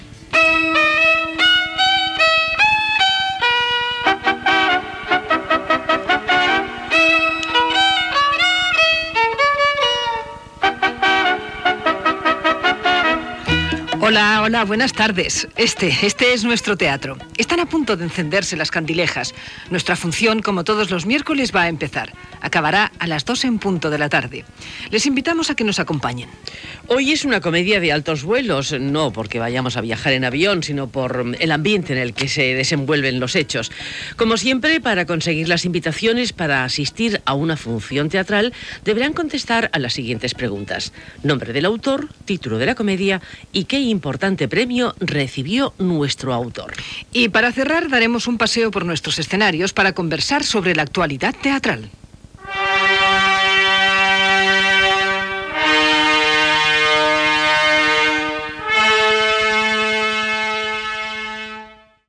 Careta del programa i presentació amb les preguntes del joc que es fa al programa.
Ficció